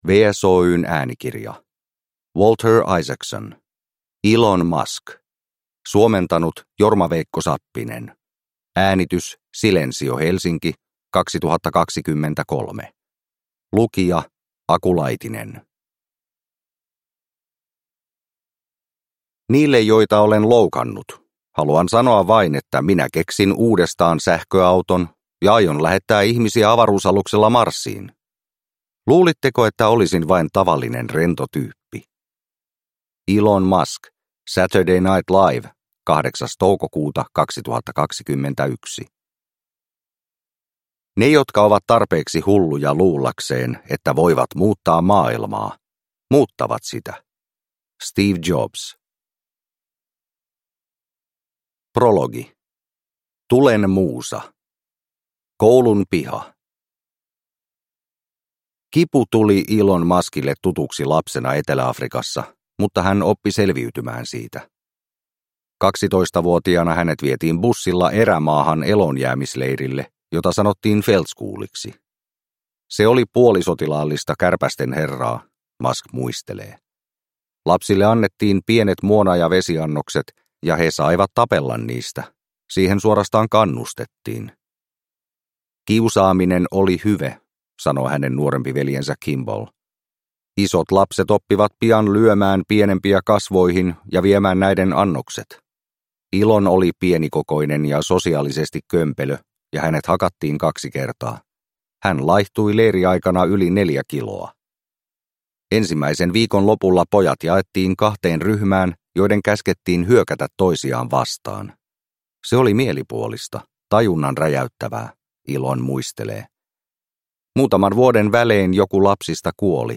Elon Musk – Ljudbok – Laddas ner